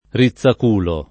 riZZak2lo] o ricciaculo [ri©©ak2lo] s. m. (zool.); pl. ‑li — es.: le formiche, e specialmente quelle che vulgarmente si appellano puzzole o rizzaculi [le form&ke, e Spe©alm%nte kU%lle ke vvulgarm%nte Si app$llano p2ZZole o rriZZak2li] (Lastri) — usato anche, ma meno bene, come agg. inv.: formiche rizzaculo